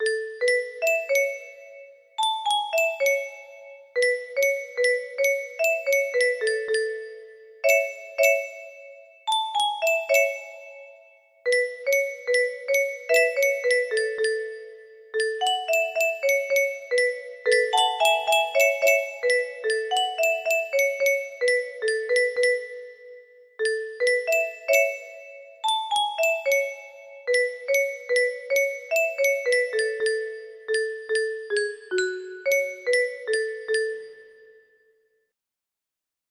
Search Me O God music box melody